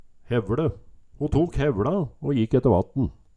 Høyr på uttala Image title